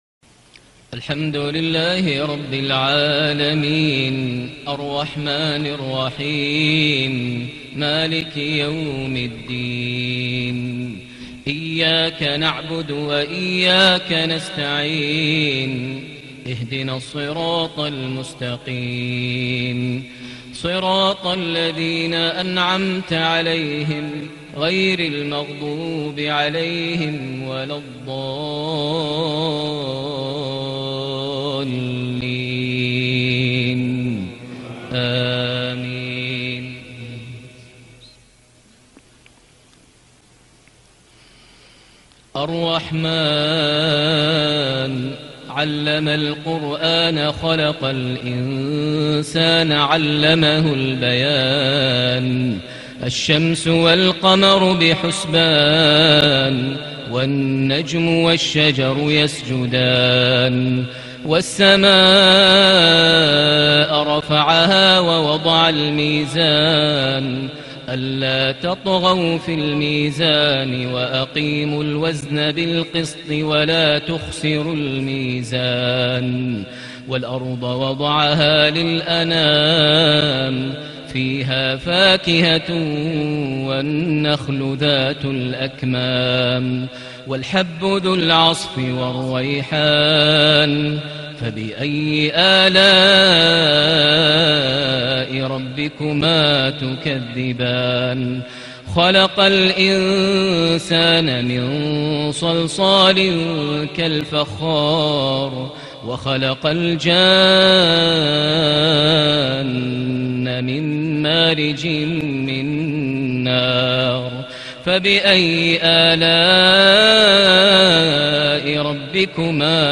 صلاة العشاء ٣ ربيع الأول ١٤٣٨هـ سورة الرحمن ١-٤٦ > 1438 هـ > الفروض - تلاوات ماهر المعيقلي